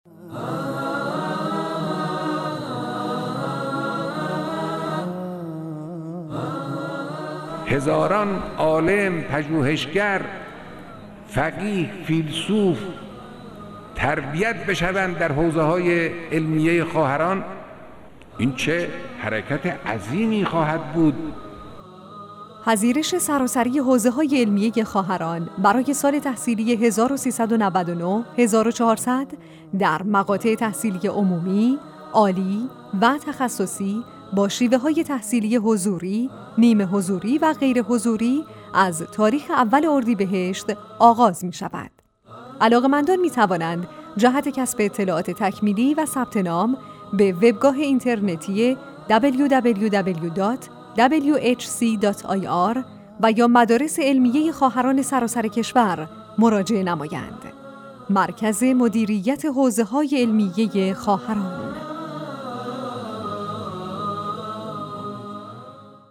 تیزر صوتی قابل استفاده در شبکه‌های رادیویی
tizer_radio.wav